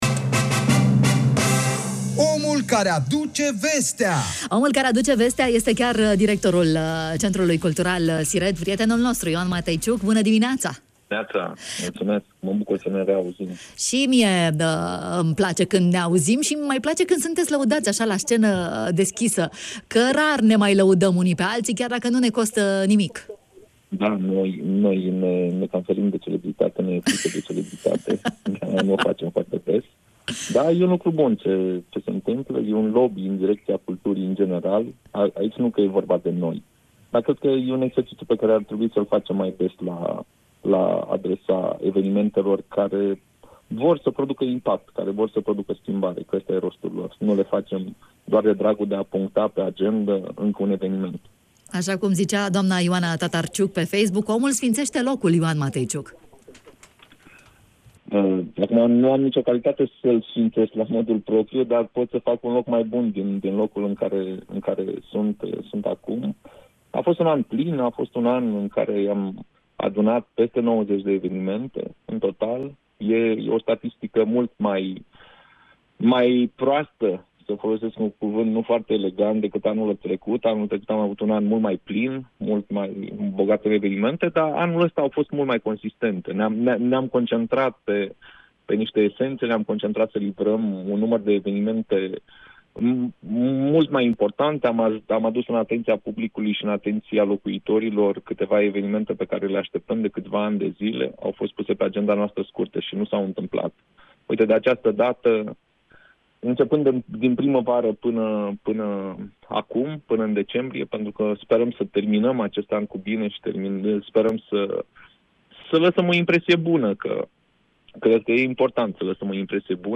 în direct la Bună Dimineața